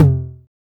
909 TOM HI.wav